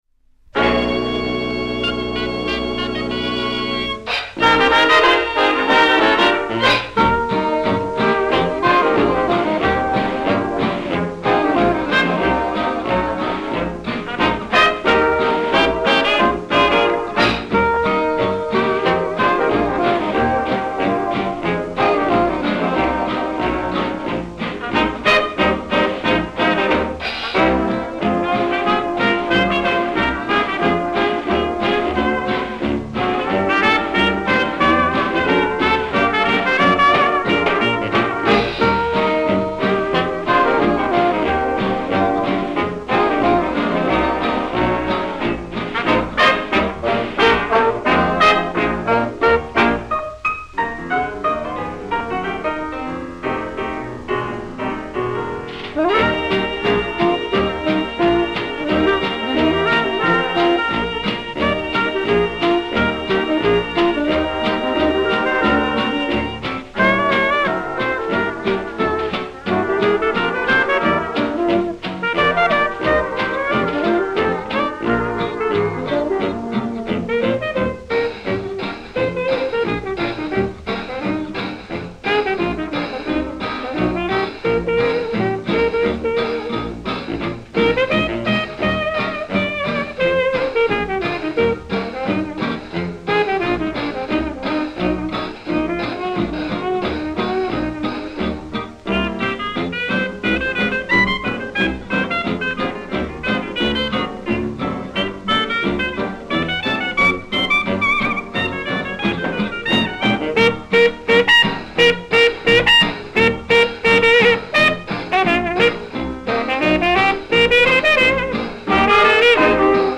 uplifting
recorded in 1929
instrumental